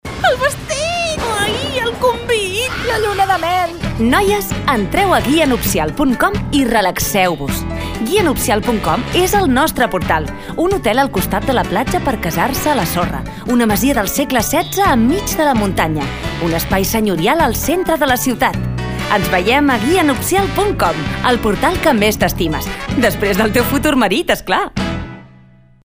A continuació us presentem les falques de publicitat que s’han emès a ràdio Flaix Bac: